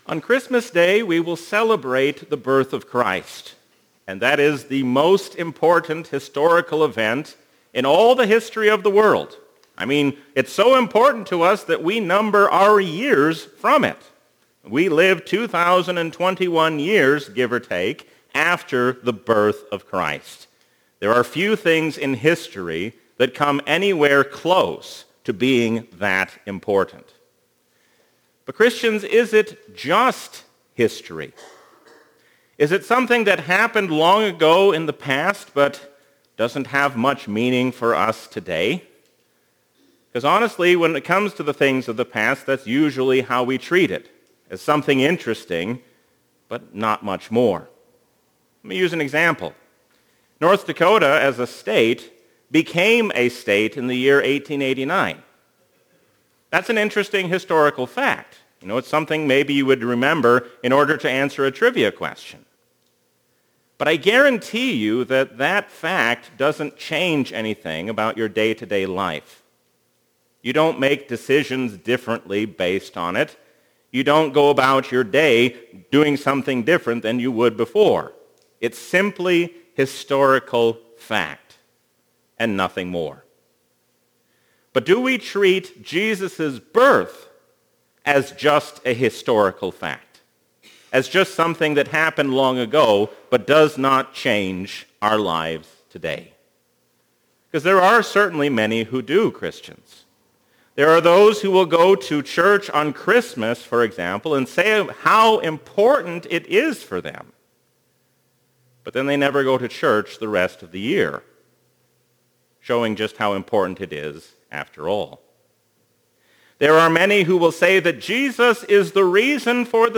A sermon from the season "Advent 2021." Peace and joy are yours in every circumstance, because Jesus hears our prayers.